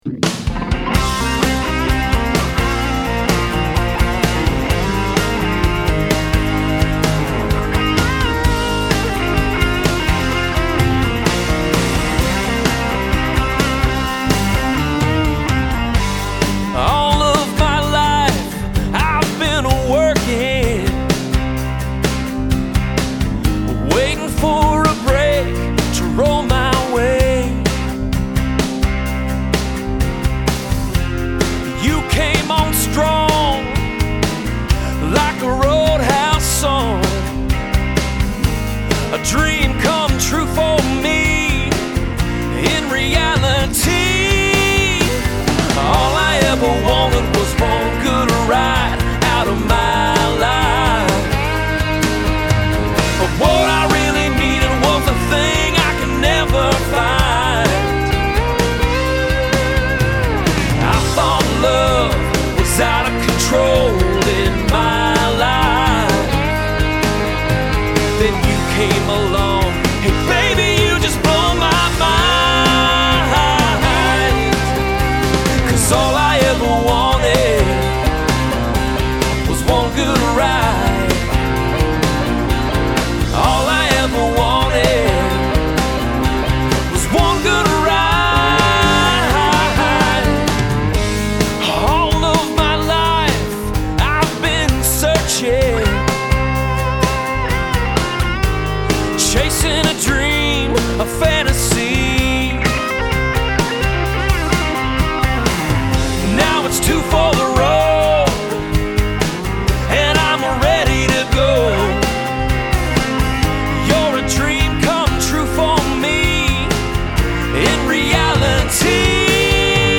With a nod to and respect for country and southern rock